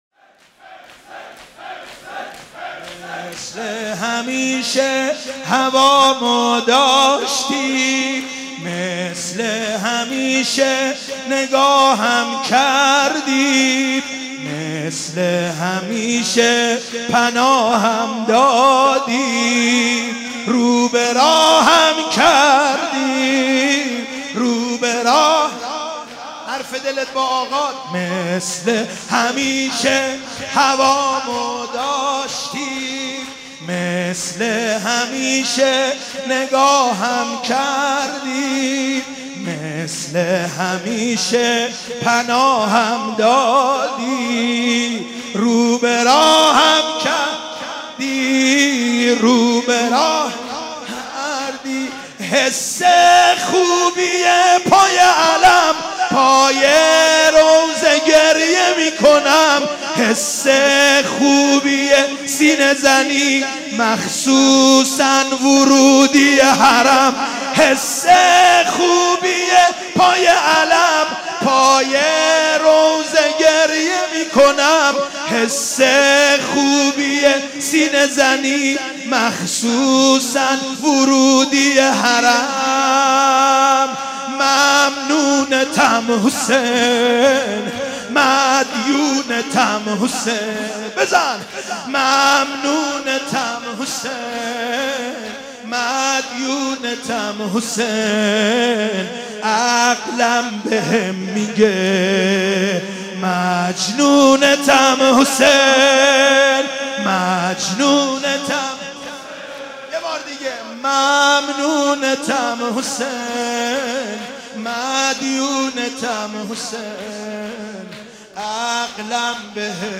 شور ١.mp3